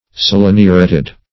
Search Result for " seleniuretted" : The Collaborative International Dictionary of English v.0.48: Seleniureted \Sel`e*ni"u*ret`ed\, a. (Chem.)
seleniuretted.mp3